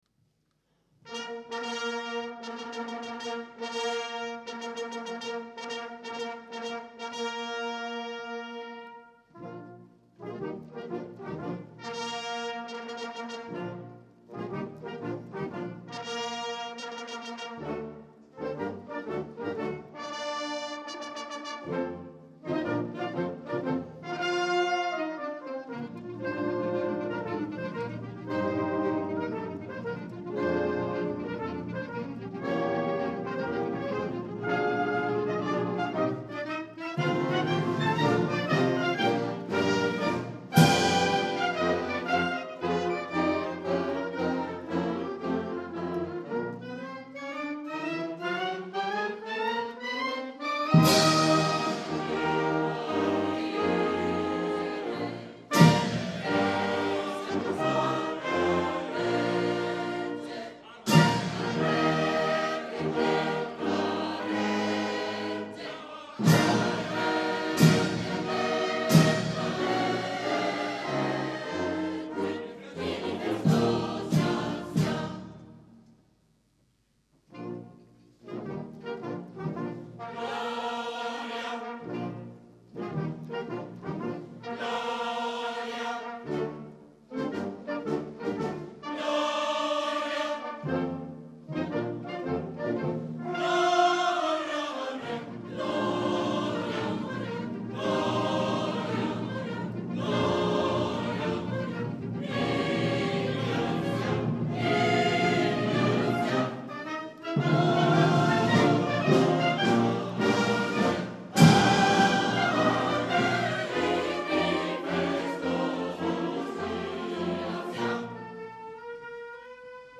I Brani Musicali sono stati registrati al Teatro "A. Bonci" di Cesena il 18 Febbraio 2001 durante il
CONCERTO LIRICO
CORALE BANDISTICO
Il Coro Lirico Città di Cesena
Banda "Città di Cesena"